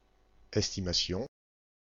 Ääntäminen
IPA: /ɛs.ti.ma.sjɔ̃/